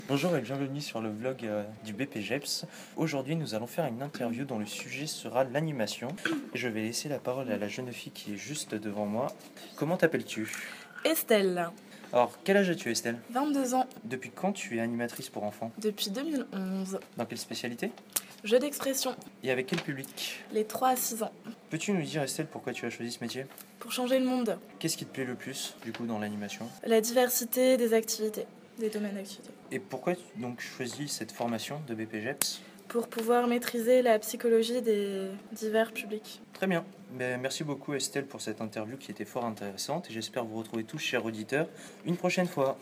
animatrice !